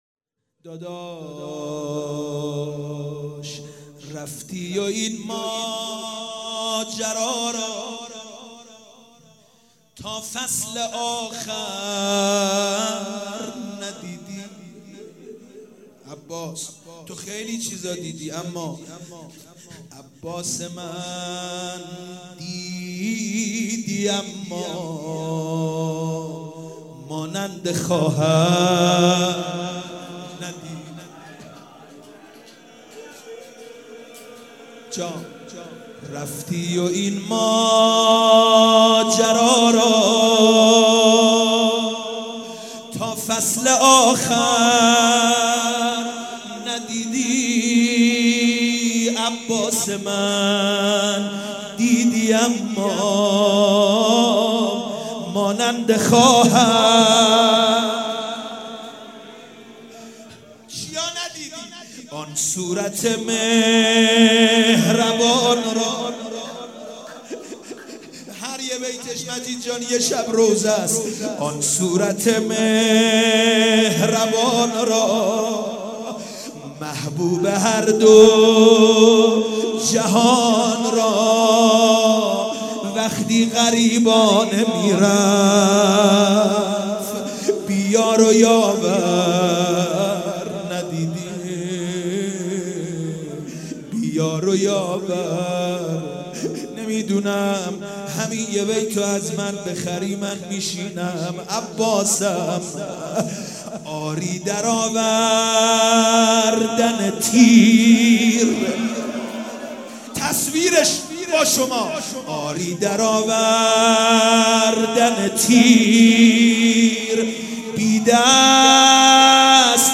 مناسبت : شب بیستم رمضان
قالب : روضه